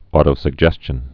tō-səg-jĕschən)